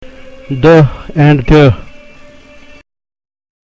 Contrast between palatal and non-palatal consonant sounds
The Pronunciation Practice